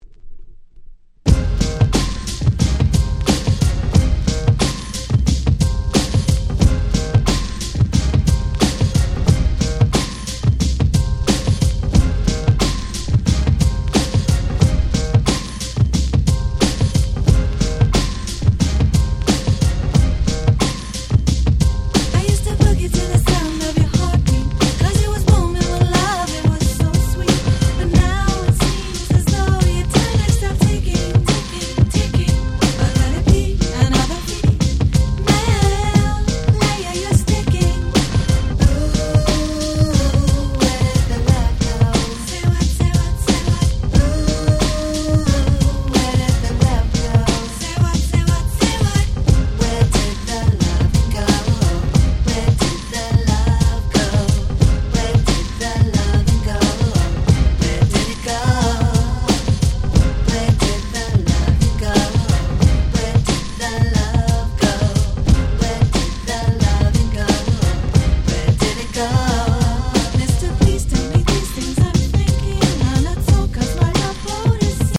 94' Very Nice R&B / Hip Hop Soul !!